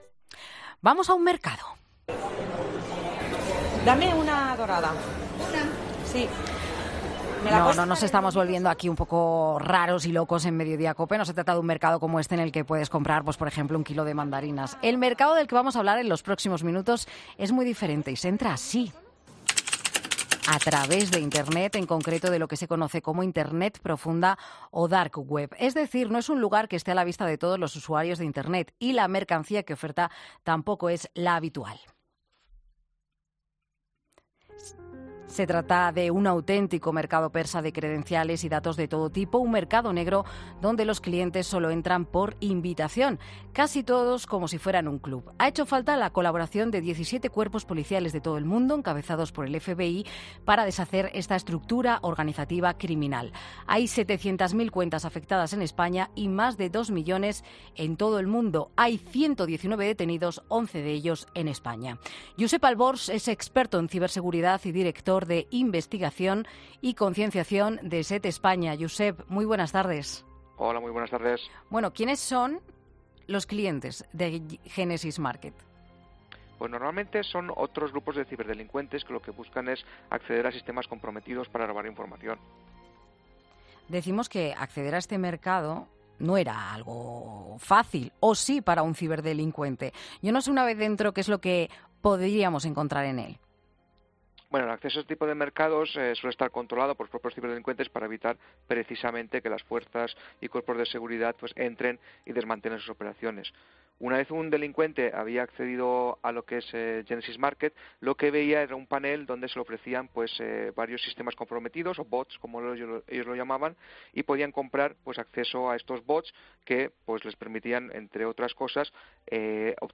experto en ciberseguridad, explica que hacían los ciberdelincuentes de Genesis Market